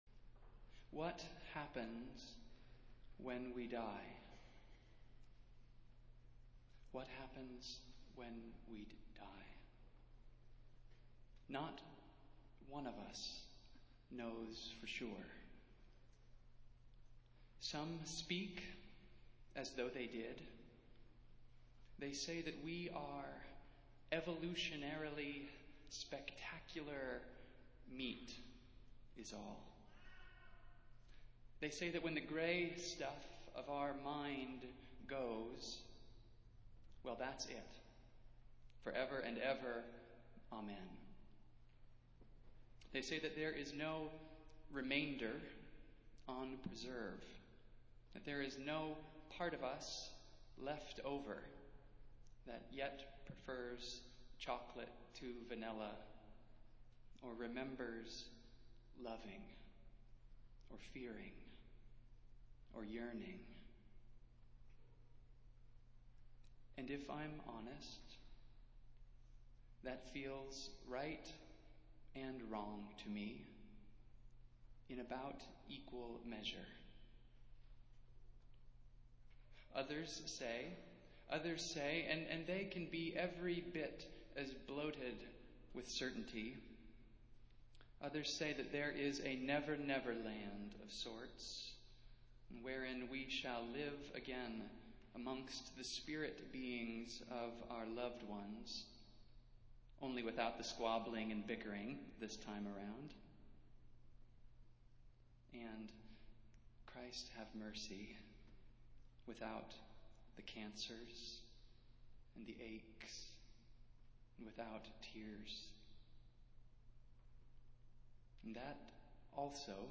Festival Worship - All Saints' Sunday